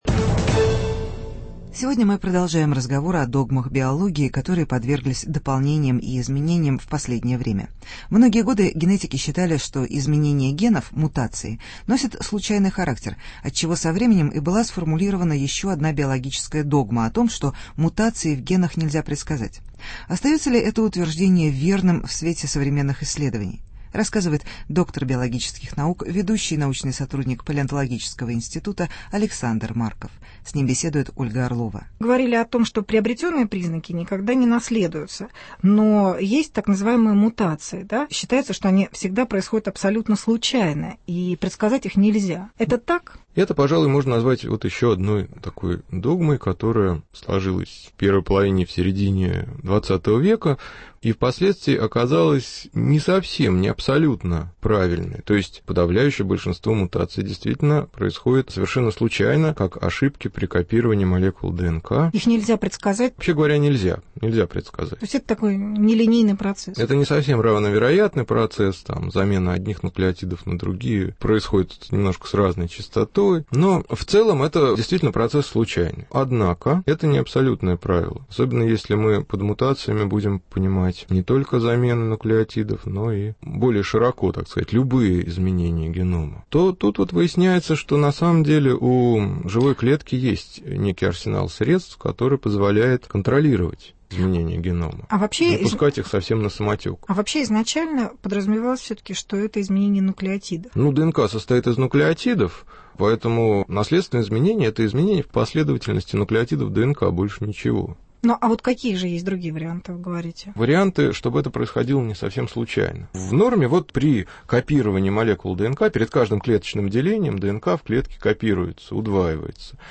Беседа из цикла « догмы биологии » - можно ли предсказать мутации в генах ?